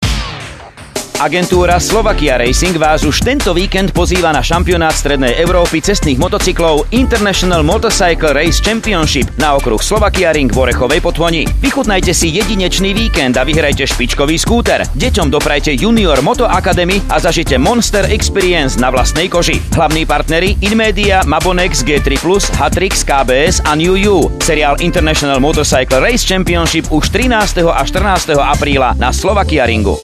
• Rádio VIVA spot vysielaný v poobedňajších hodinách – môžete si ho vypočuť
IMRC-radio-spot.mp3